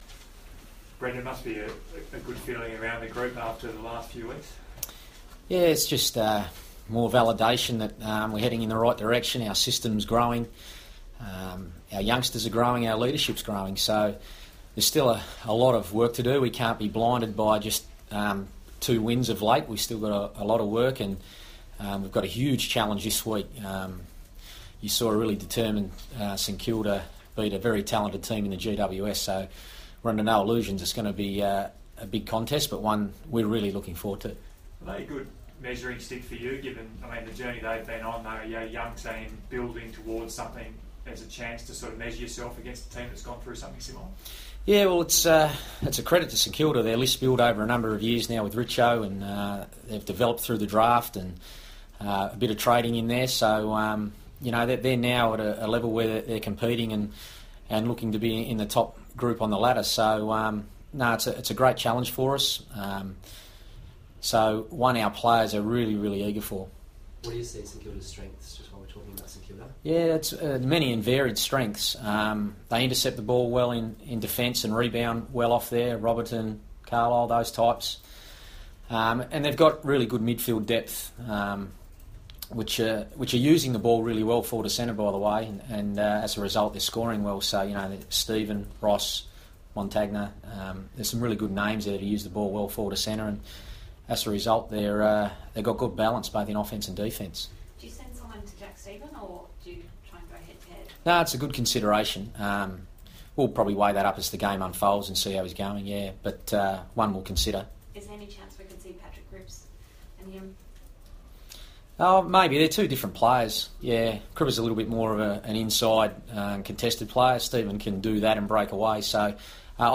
Brendon Bolton press conference - May 12
Carlton coach Brendon Bolton fronts the media ahead of the Blues' Round 8 clash with St Kilda.